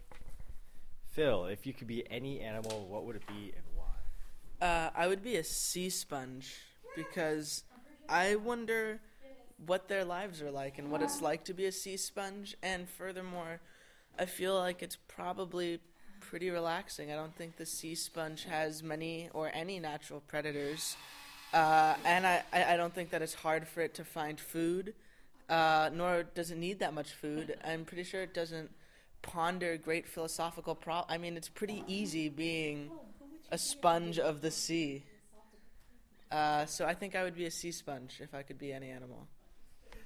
Location: Next to the post office in the basement of the Student Center
Sounds heard: Talking, faint footsteps, door slamming
field-recording2.mp3